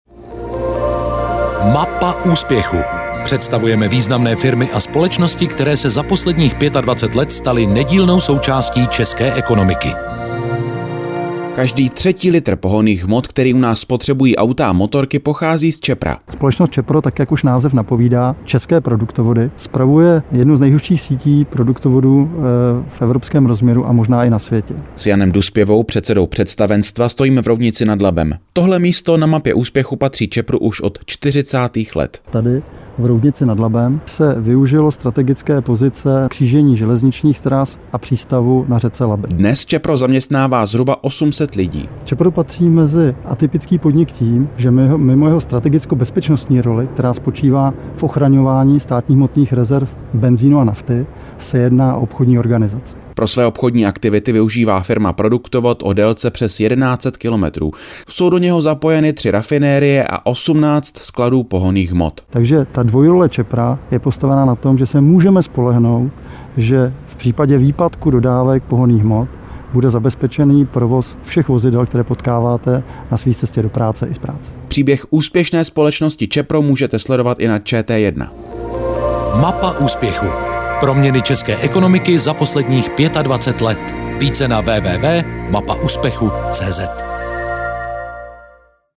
ČEPRO, a.s. ve vysílání Českého rozhlasu - CZECH TOP 100